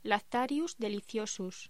Locución: Lactarius deliciosus
voz